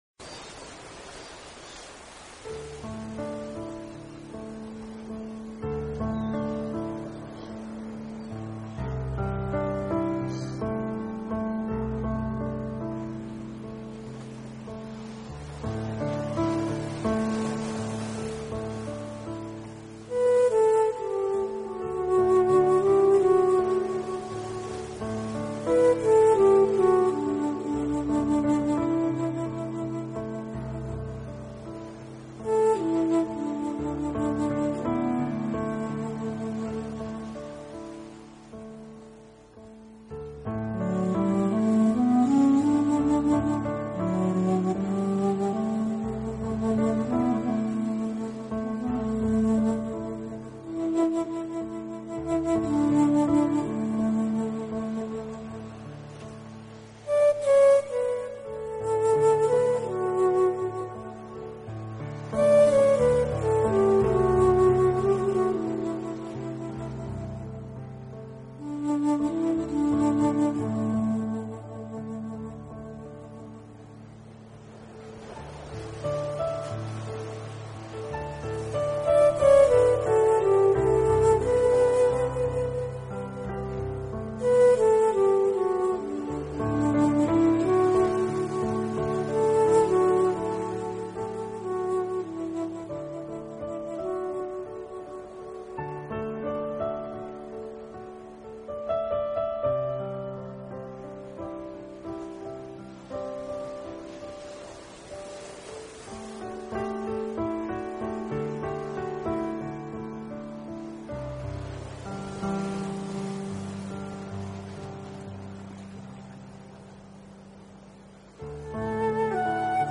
音乐类型：New Age
混合使用长笛、钢琴和键盘，并延续他一贯的曲风，展现出更多美